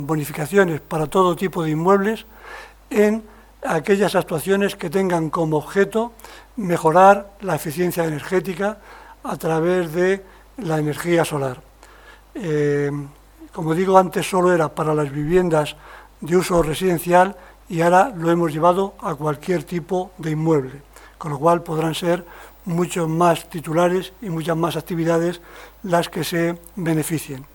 El concejal de Hacienda, José Pablo Sabrido, ha informado en rueda de prensa del proyecto de modificación de ordenanzas fiscales y precios públicos para el año 2022 que se aprobará este miércoles como trámite inicial en la Junta de Gobierno Local.
AUDIOS. José Pablo Sabrido, concejal de Hacienda
jose-pablo-sabrido_bonificacion-ibi-inmuebles-eficientes-energia-solar.mp3